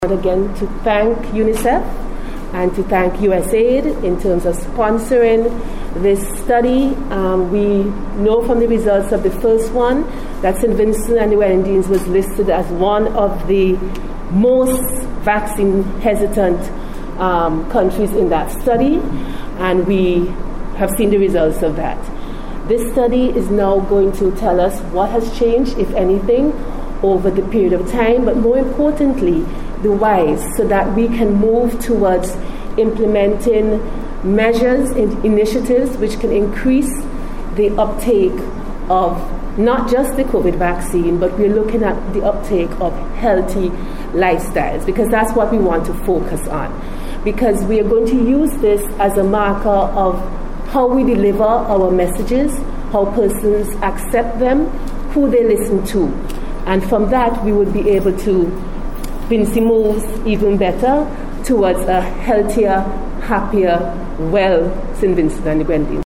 This morning’s launch at the Bechcombers’s Hotel, heard remarks from Chief Medical Officer Dr. Simone Keizer Beache, who said the results of the study will provide valuable information to guide  the Ministry of Health, in shaping its polices.